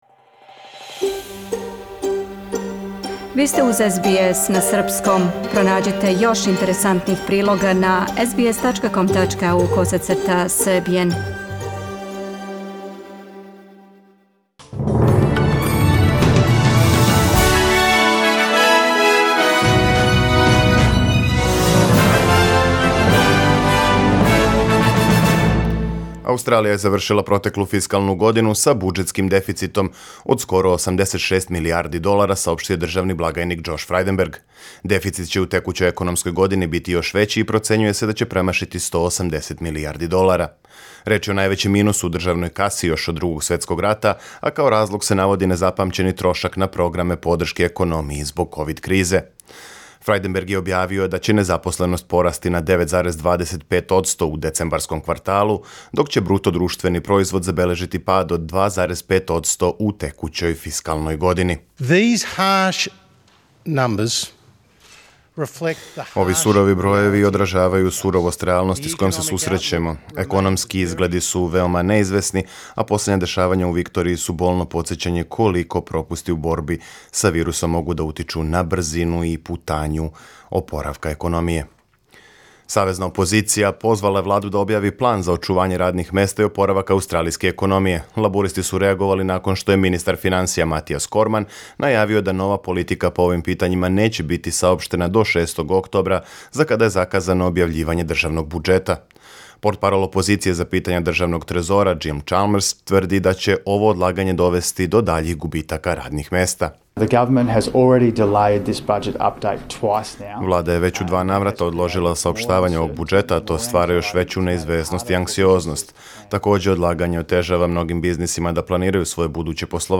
Преглед вести за 23. јул 2020.